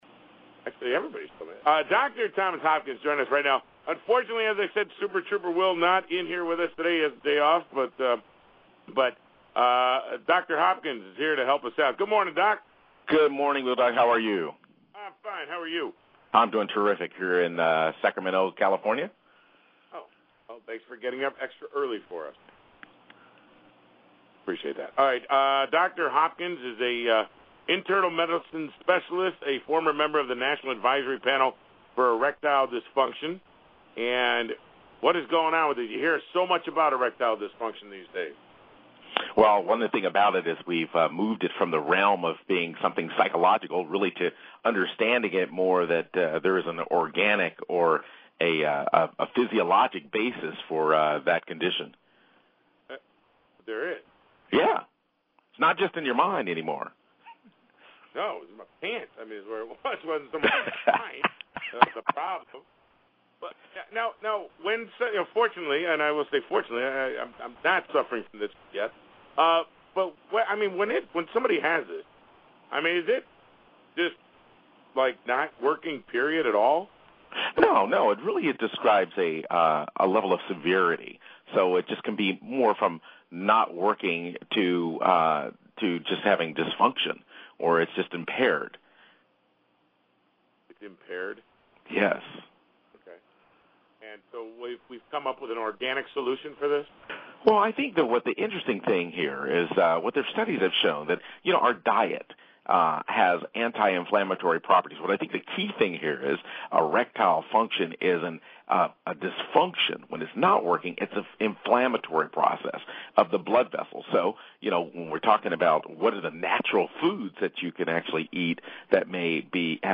Radio interviews:
6:20 am Pacific Time/9:20 am Eastern Time: seven minute taped interview on WOCM-FM (MD/DE/VA).